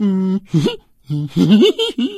PigGrin 03.wav